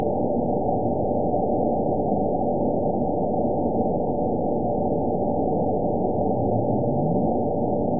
event 911119 date 02/11/22 time 00:40:30 GMT (3 years, 3 months ago) score 9.24 location TSS-AB01 detected by nrw target species NRW annotations +NRW Spectrogram: Frequency (kHz) vs. Time (s) audio not available .wav